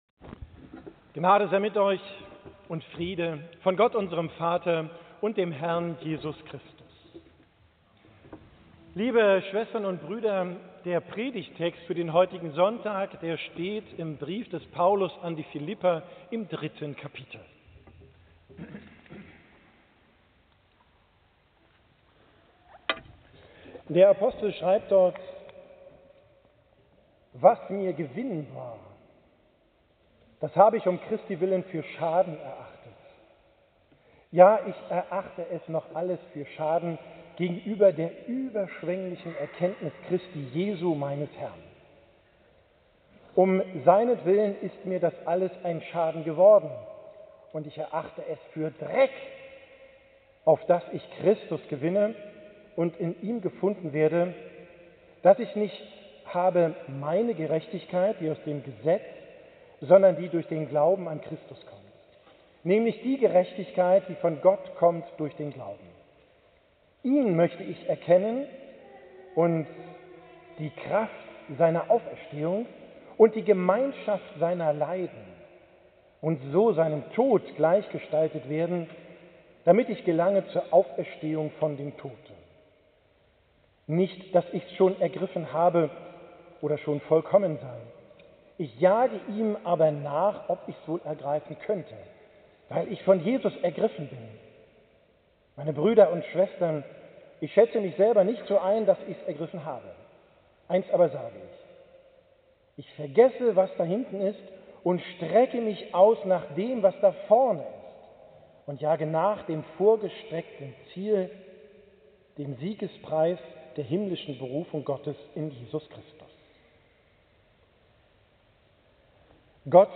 Predigt vom 9.